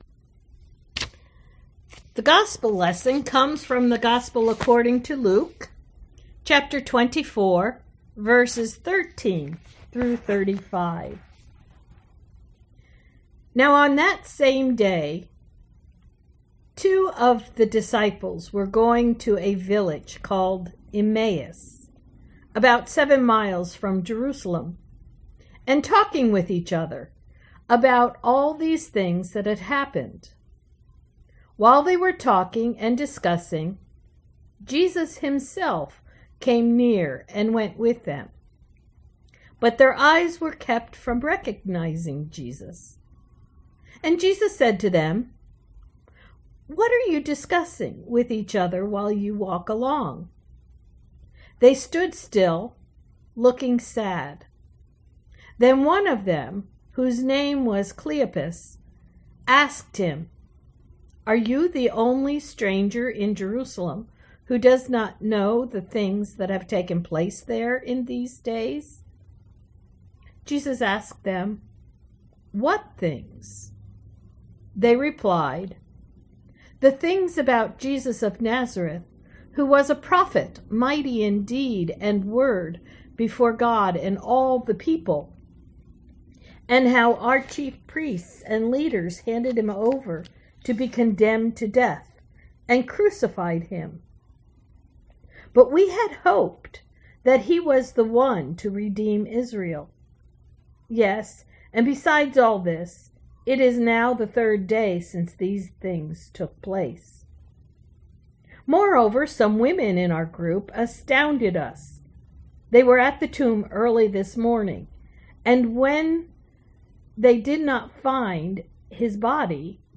Hymn: No. 700 - Abide with Me